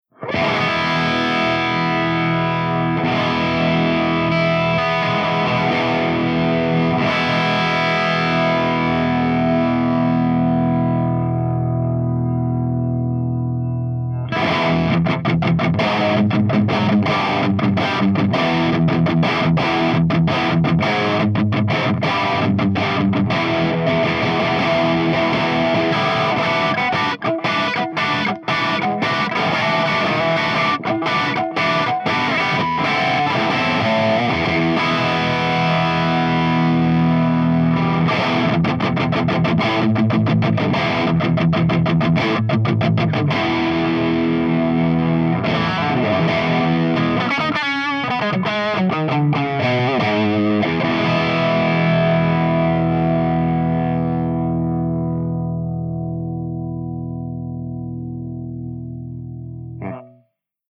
148_MESA SINGLE RECTIFIER_CH2CRUNCH_V30_HB